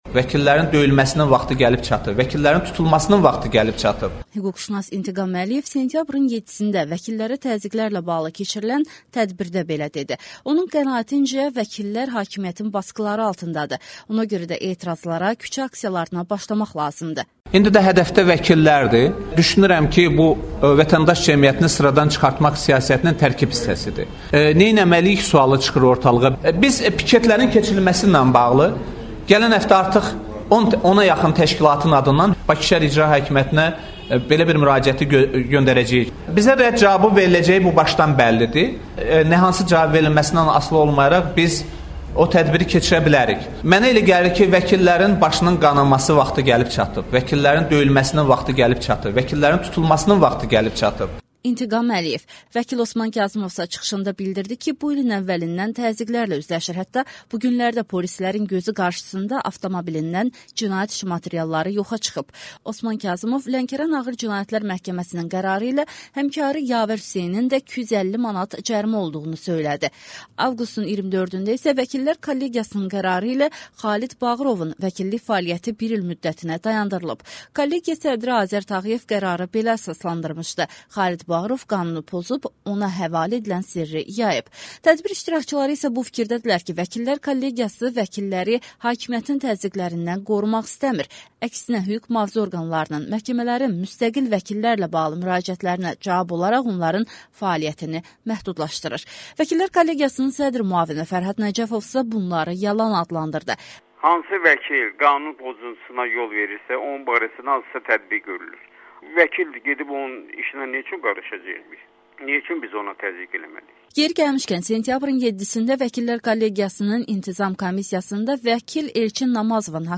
Hüquqşünas İntiqam Əliyev sentyabrın 7-də vəkillərə təzyiqlərlə bağlı keçirilən tədbirdə belə deyib